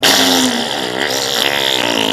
Gross Wet Fart Bouton sonore